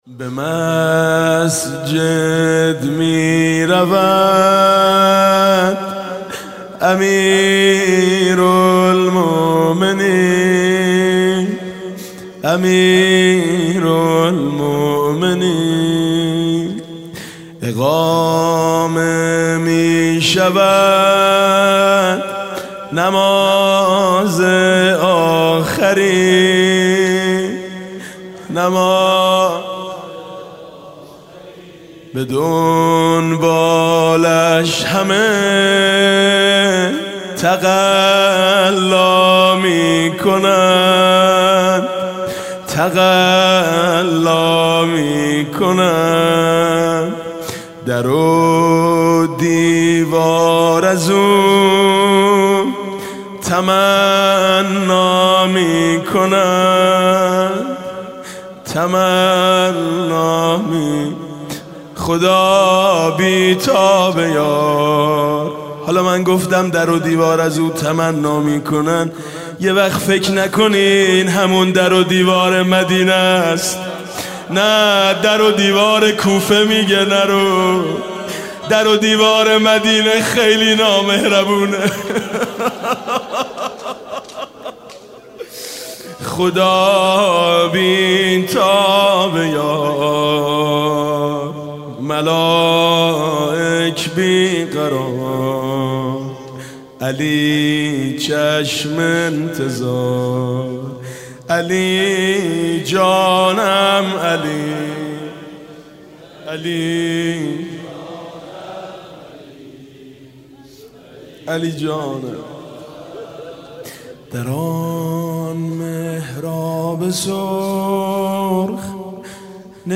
زمزمه